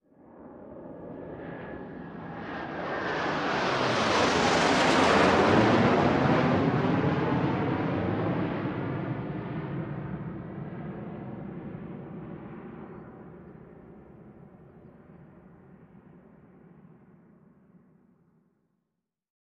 AFX_F18_FLYBY_4_DFMG.WAV
F-18 Flyby 4